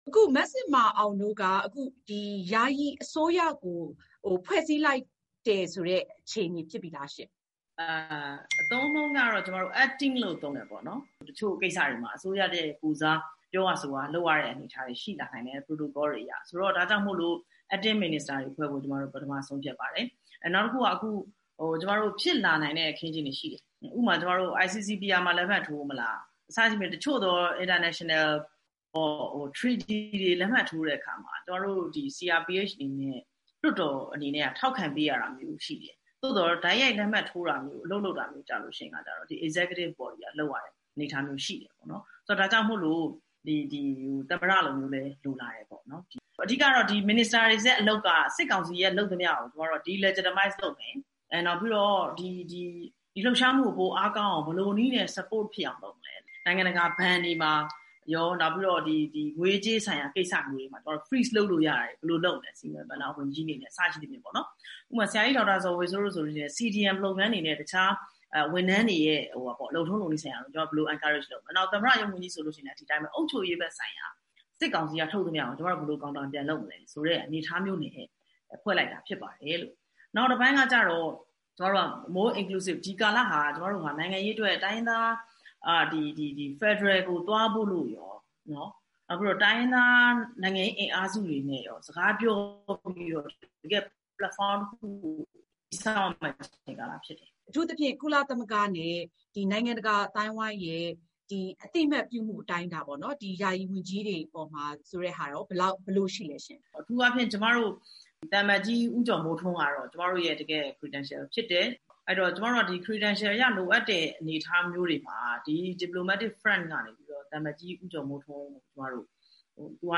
CRPH ခန့် နိုင်ငံခြားရေးဝန်ကြီး ဒေါ်ဇင်မာအောင်နဲ့ ဆက်သွယ်မေးမြန်းချက်